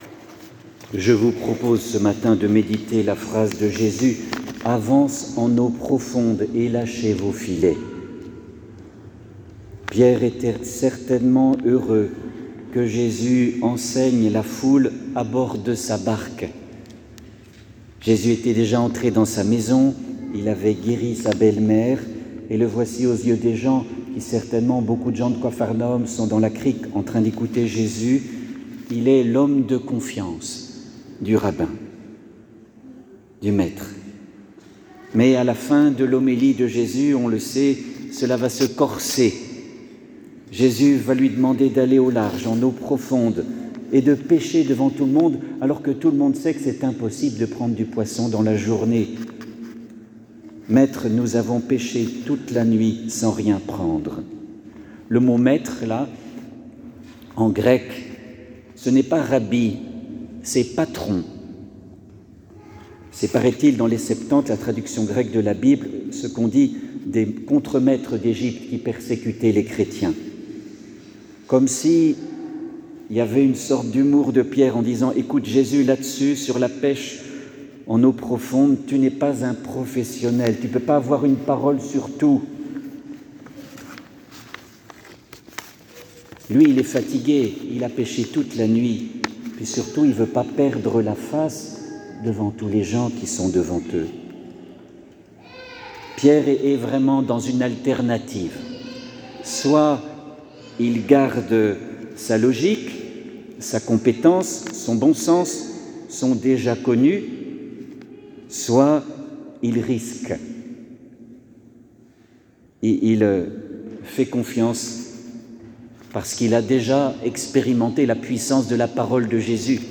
Homélie
Homelie-Avance-en-eau-profonde-dimanche-9-fevrier.mp3